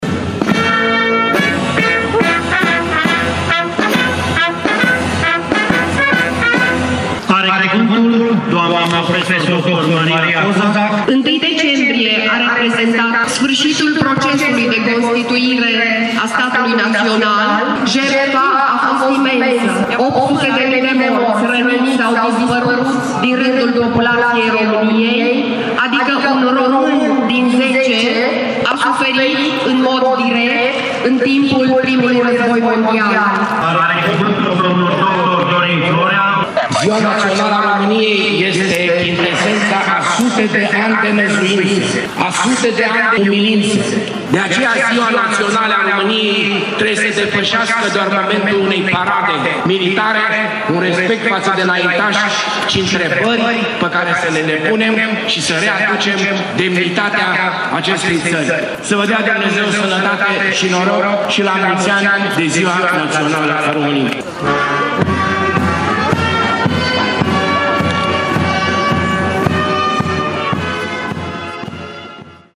În Piața Victoriei, mii de târgumureșeni au asistat apoi la ceremonie militară și religioasă, în cadrul căreia a fost prezentată semnificația istorică a evenimentului: